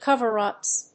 /ˈkʌvɝˌʌps(米国英語), ˈkʌvɜ:ˌʌps(英国英語)/